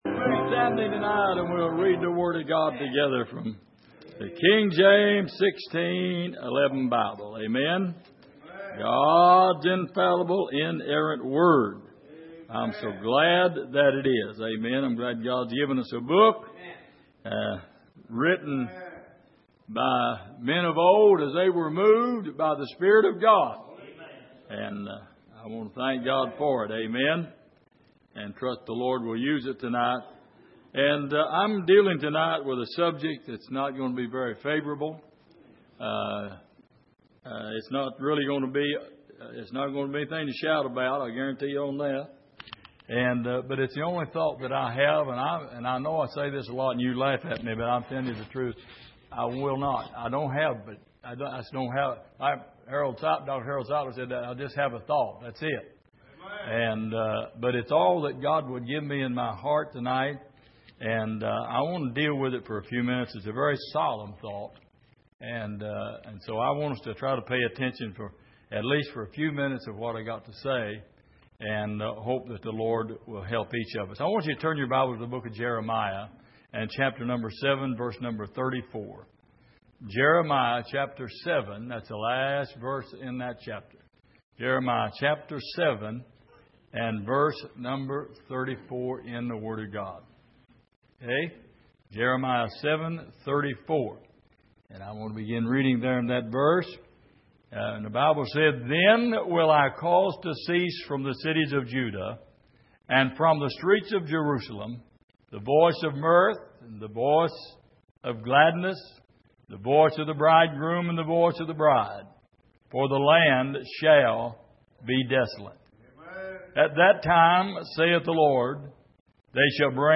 Passage: Jeremiah 7:34-8:3 Service: Sunday Evening